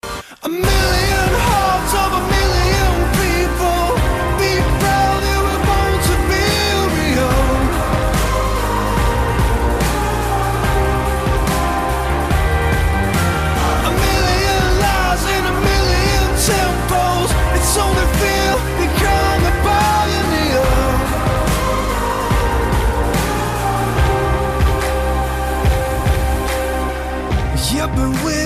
• Качество: 172, Stereo
мужской вокал
спокойные